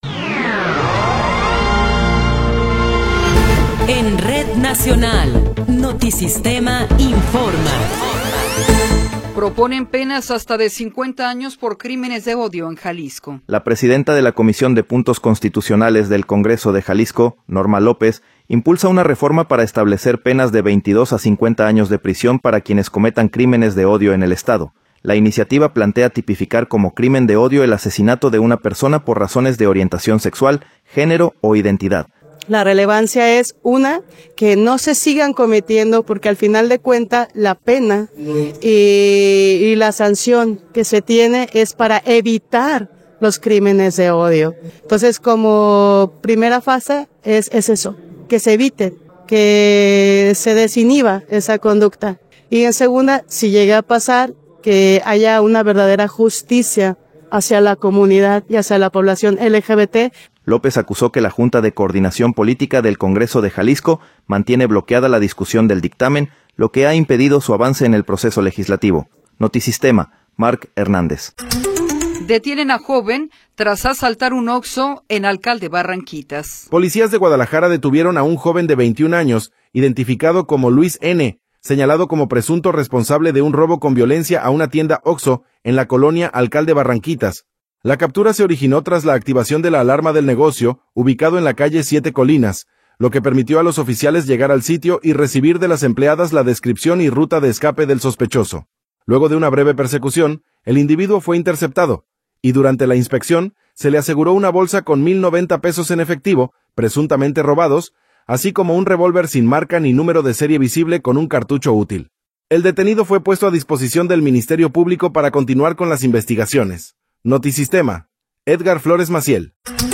Noticiero 10 hrs. – 16 de Febrero de 2026
Resumen informativo Notisistema, la mejor y más completa información cada hora en la hora.